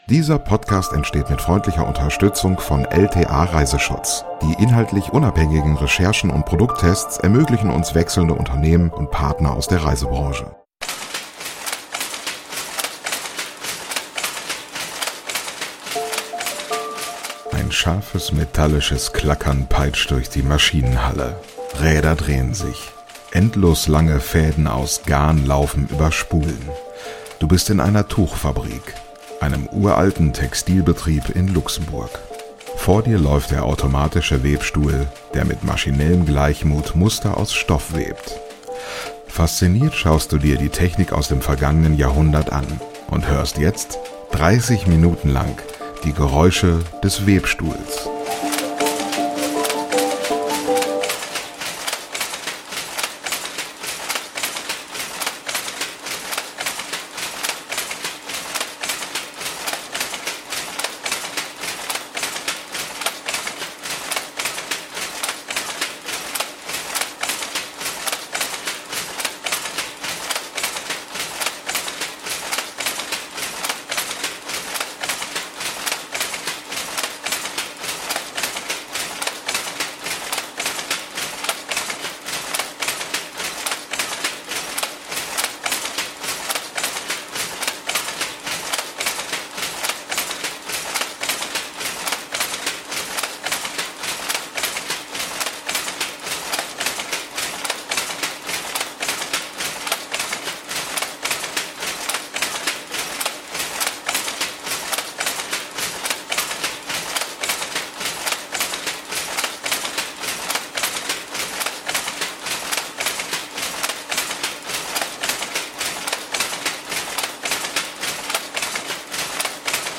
ASMR Alter Webstuhl in Luxemburg: Ambient 3D-Sound zum Einschlafen ~ Lieblingsreisen - Mikroabenteuer und die weite Welt Podcast
Ein scharfes, metallisches Klackern peitscht durch die Maschinenhalle. Rädern drehen sich, endlos lange Fäden aus Garn laufen über Spulen. Du bist in einer Tuchfabrik, einem uralten Textilienbetrieb in Luxemburg.
Vor dir läuft der automatische Webstuhl, der mit maschinellem Gleichmut Muster aus Stoff webt. Fasziniert schaust du dir die Technik aus dem vergangenen Jahrhundert an - und hörst jetzt 30 Minuten lang die Geräusche des Webstuhls.